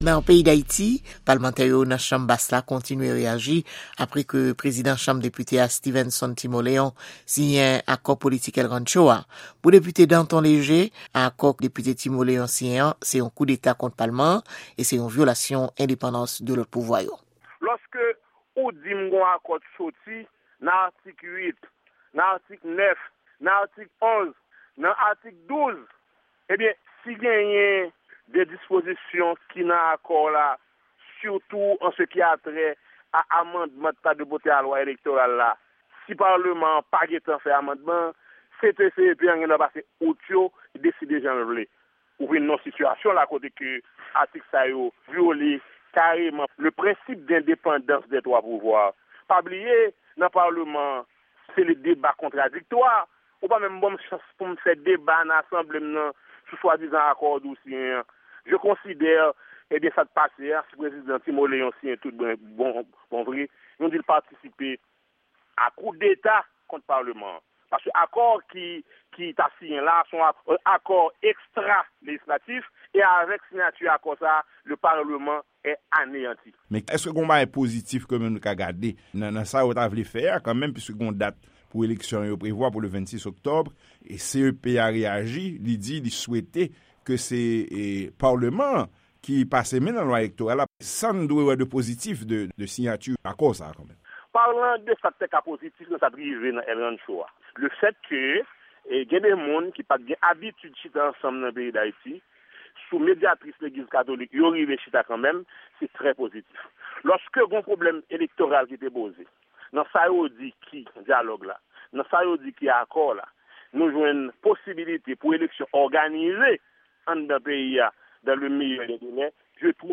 Repòtaj: Plis Reyaksyon sou Akò El Rancho a - Plan Daksyon an Ka Dezas